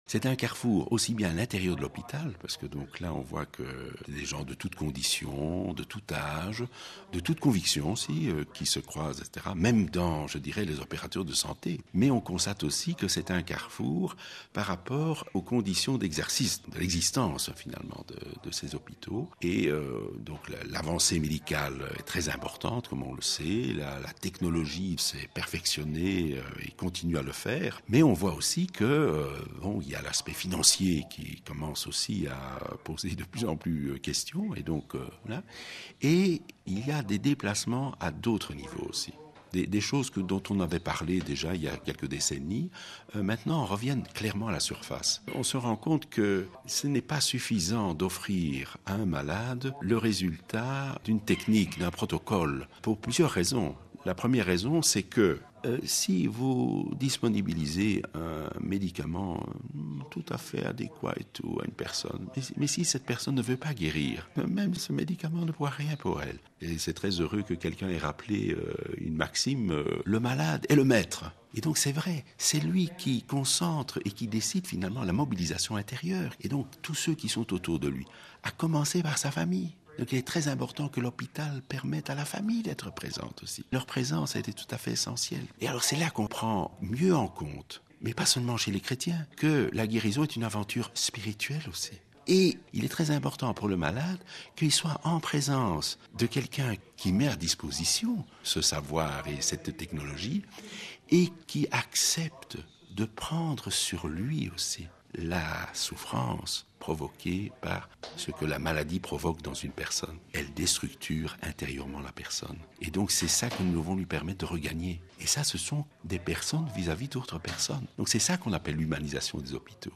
Il est interrogé par